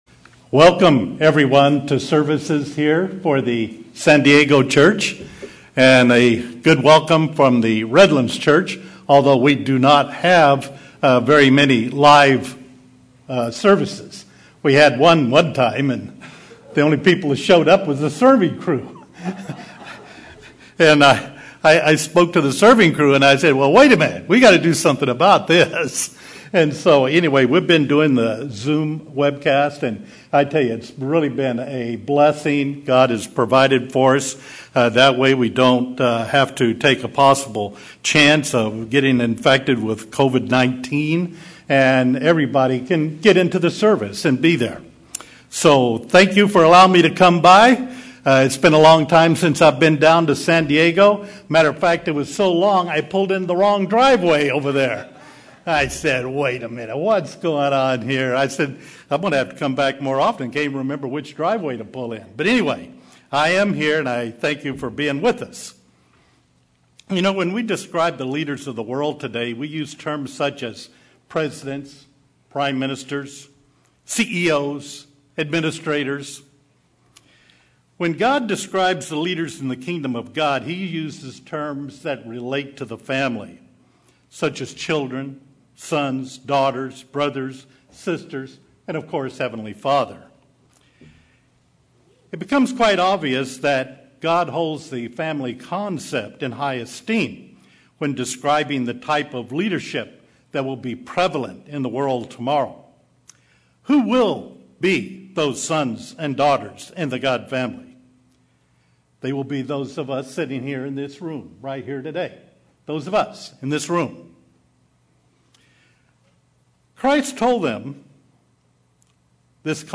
Given in San Diego, CA